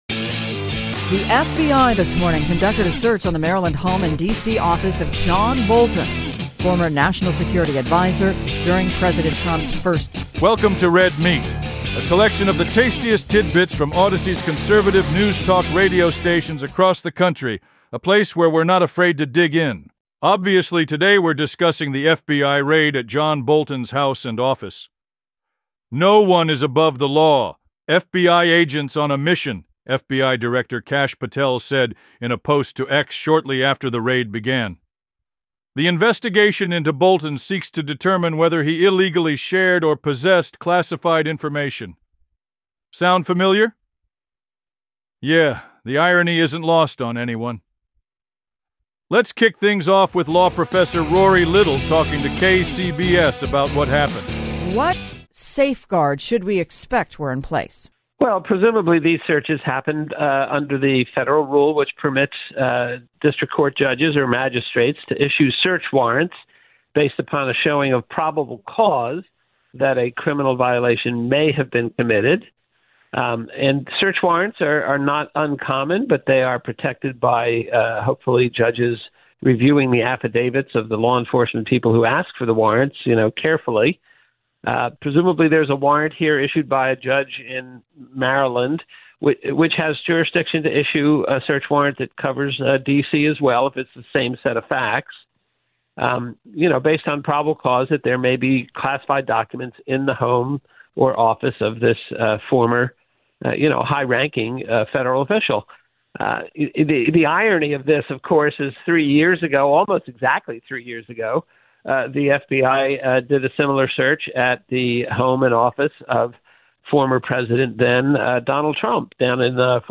Be a guest on this podcast Language: en Genres: News , News Commentary Contact email: Get it Feed URL: Get it iTunes ID: Get it Get all podcast data Listen Now... 'No one is above the law:' FBI raid on John Bolton brings up old wounds and new questions, plus does RFK really work out in jeans?